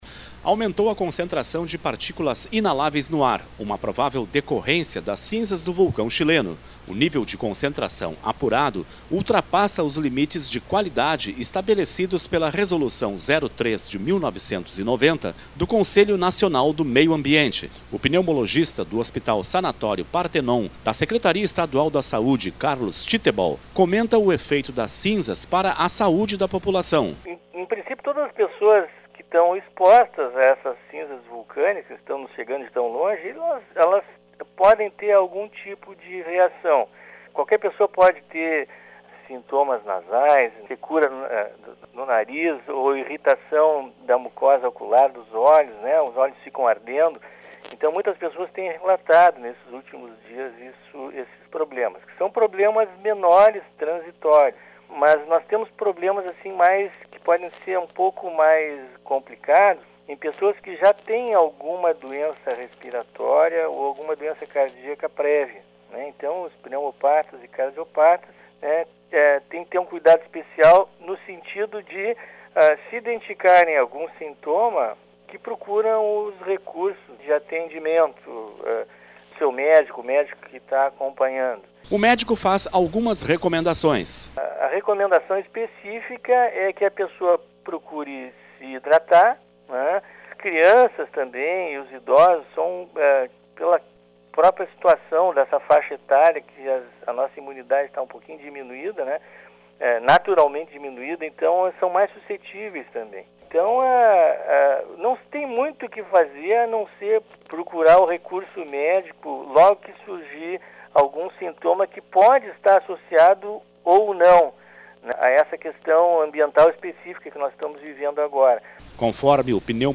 Pneumologista fala dos riscos das cinzas vulcânicas à saúde da população